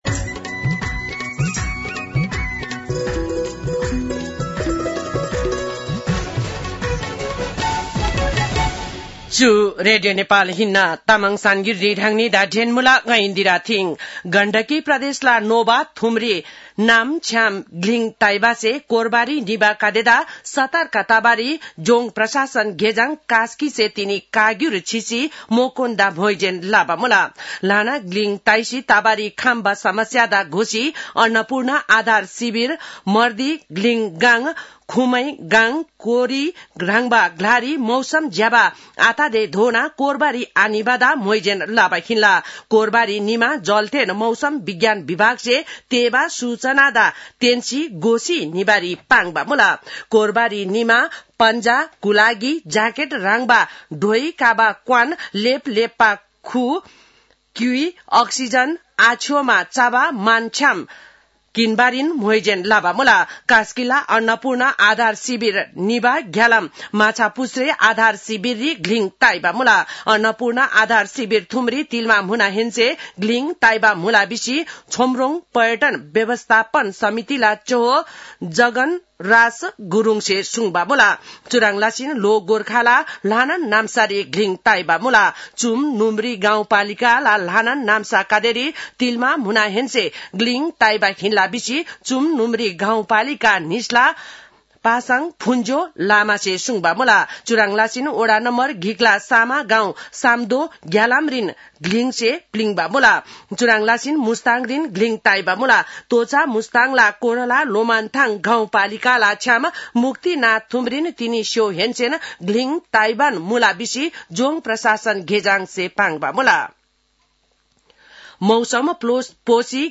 तामाङ भाषाको समाचार : ११ कार्तिक , २०८२